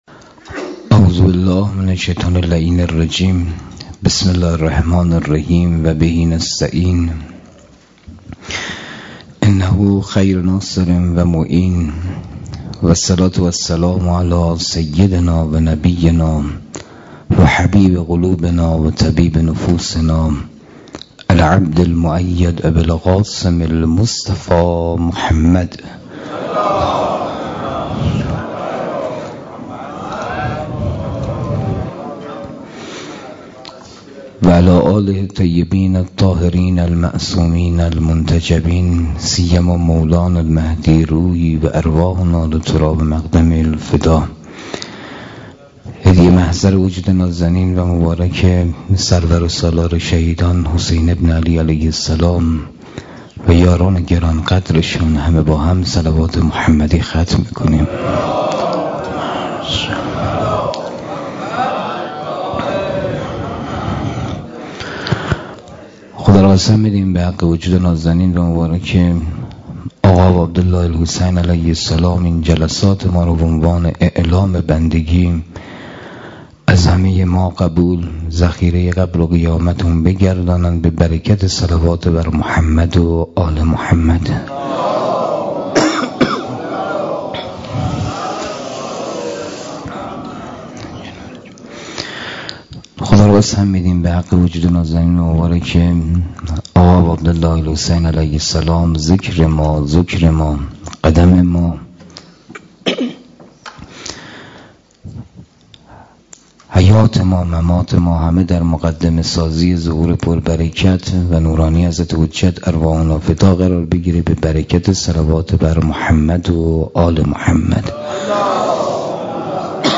مسجد حضرت خدیجه